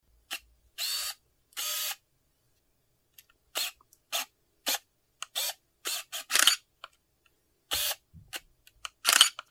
دانلود صدای دوربین 7 از ساعد نیوز با لینک مستقیم و کیفیت بالا
جلوه های صوتی